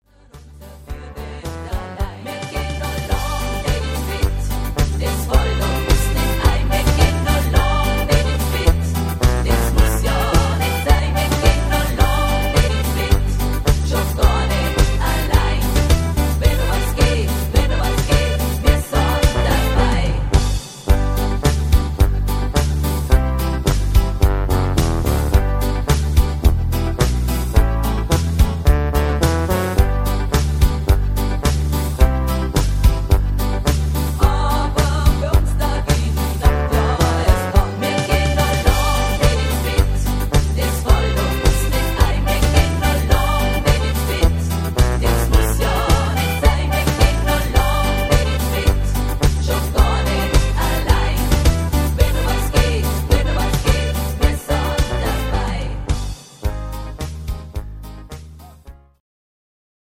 Rhythmus  Polka Beat